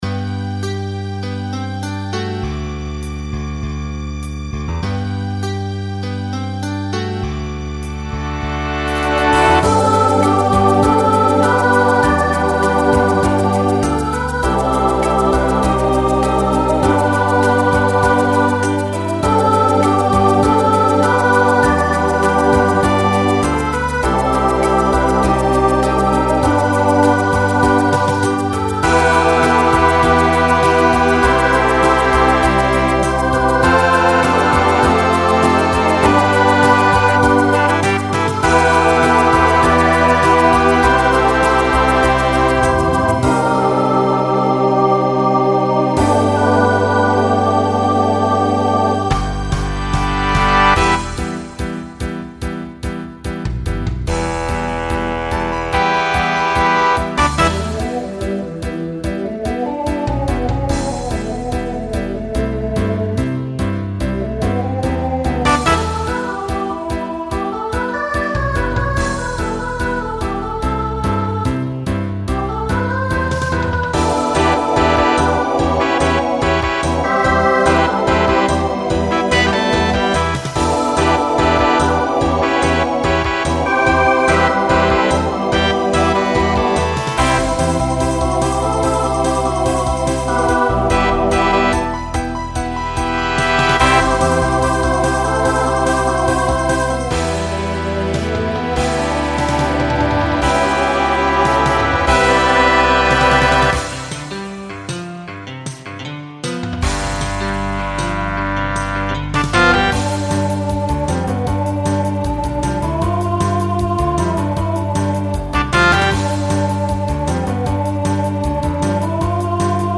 SATB Instrumental combo
Pop/Dance , Rock Decade 1960s Show Function Closer